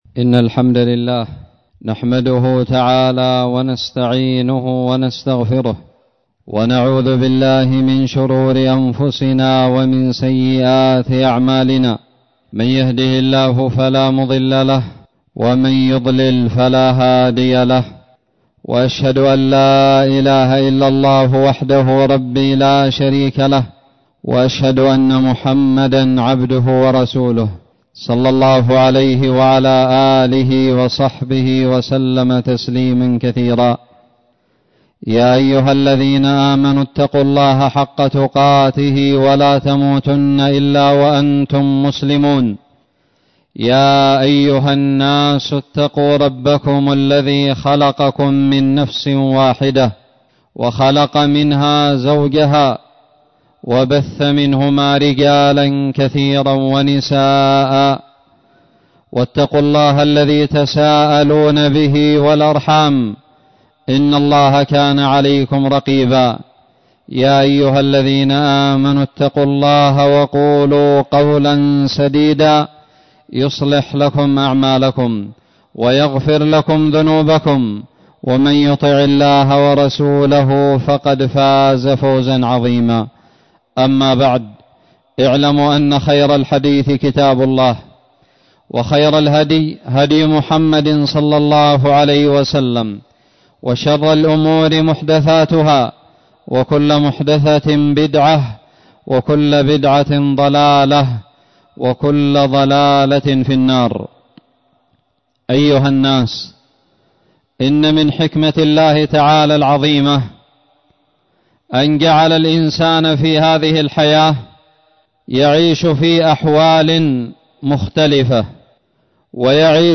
خطب الجمعة
ألقيت بدار الحديث السلفية للعلوم الشرعية بالضالع في 5 صفر 1441هــ